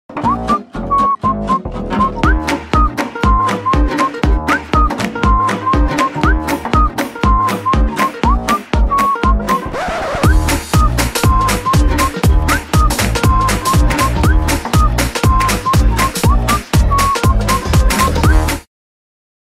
• Качество: 128, Stereo
позитивные
свист
без слов
легкие
насвистывание
Позитивная музычка